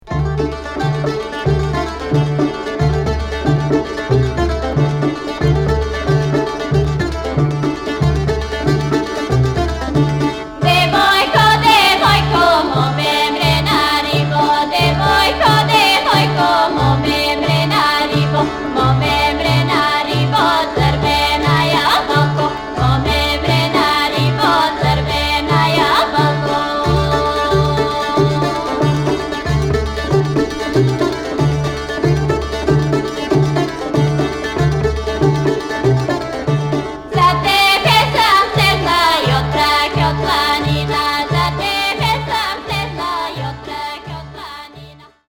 憂色を帯びたしなやかな声を聴かせる土着民謡や、素朴な響きに満ちた民族楽器の調べなど、生活の中の庶民的な音楽風景を真空パック。
キーワード：現地録り　ブルガリア 　バルカン